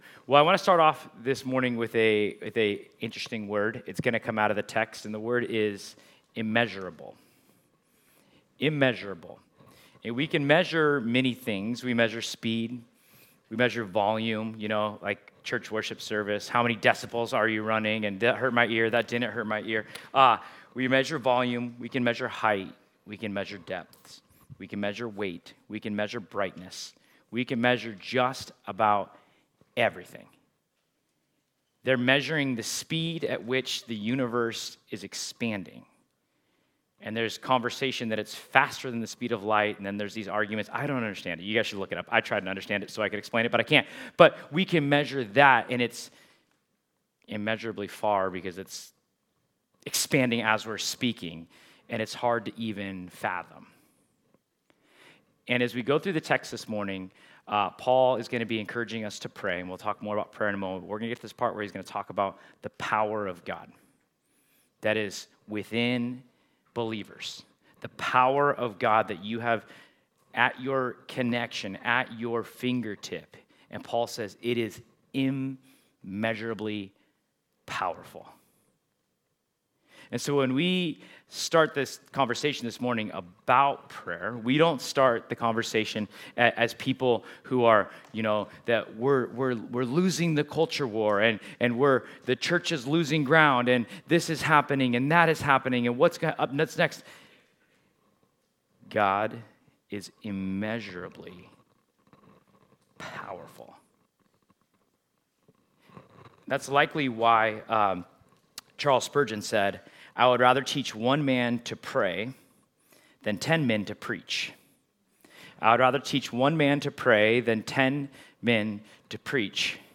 Ephesians 1:15-23 Service Type: Sunday The key purpose for all followers of Jesus is to know God and make Him known.